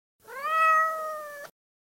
miaow.mp3